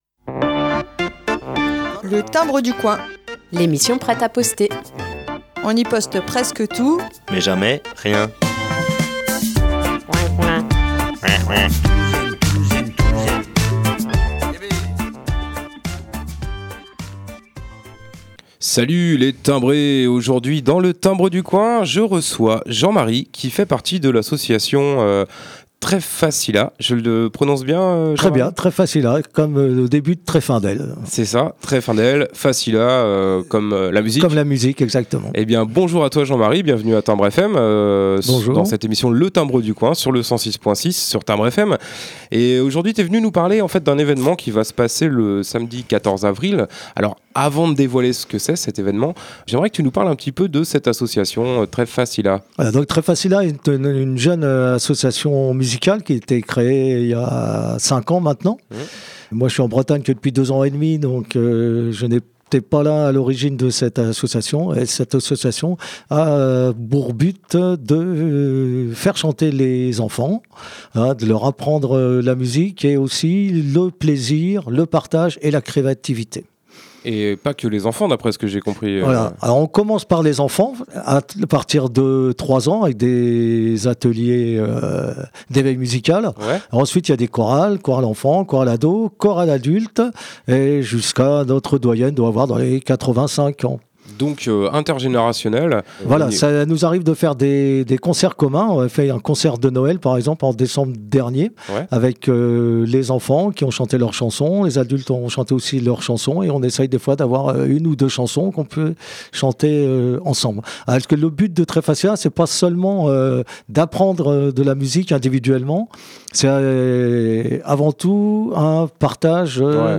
Interview/invité : Association « Treff Fa Si La » de TREFFENDEL